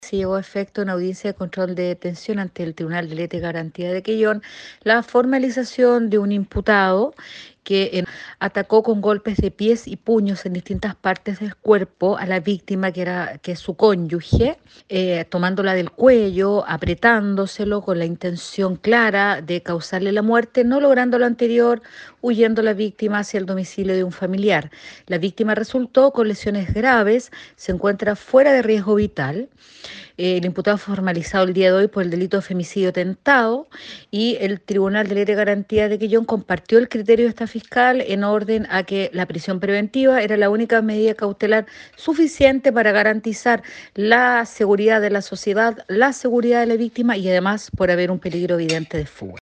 Los hechos ocurrieron el día sábado cuando este individuo por causas que se investigan golpeó de pies y puños a la víctima, en este caso, su conviviente, tal como lo informó la fiscal de Quellón, Karin Alegría.
24-FISCAL-KARYN-ALEGRIA-FEMICIDIO-TENTADO.mp3